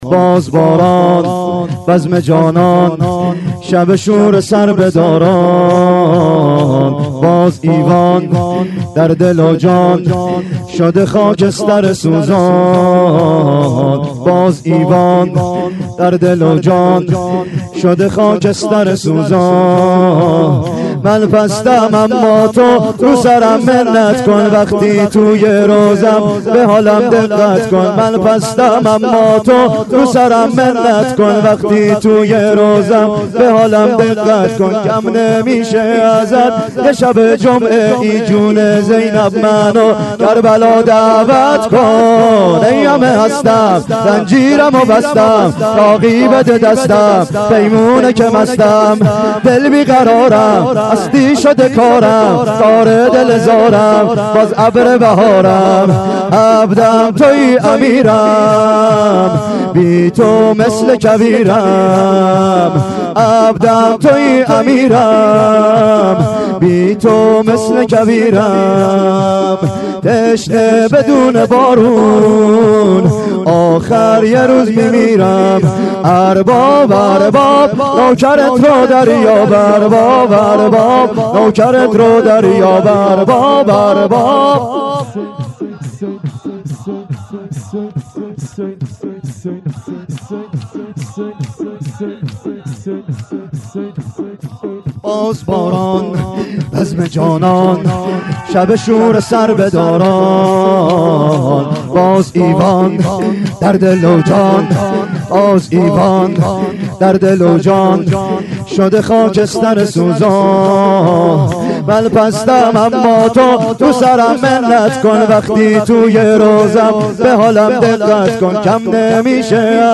گلچین شورهای محرم 93
شور شب هشتم : باز باران بزمه جانان شبه شوره سربدارن